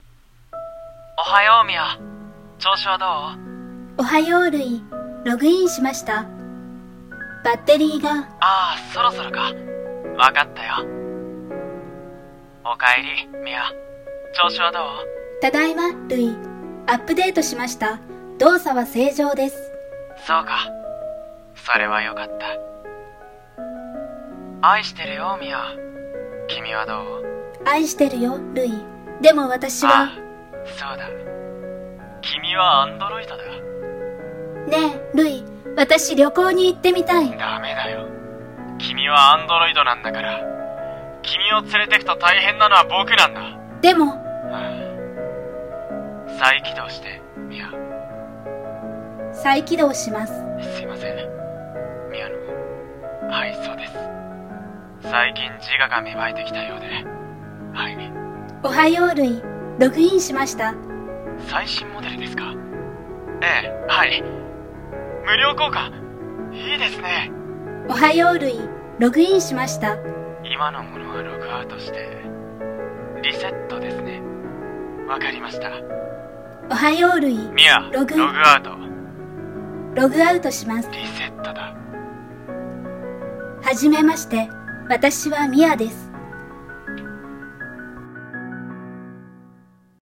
声劇【アンドロイド彼女】 （ コラボ コラボ用 コラボ募集 掛け合い 二人声劇 )